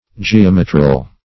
Geometral \Ge*om"e*tral\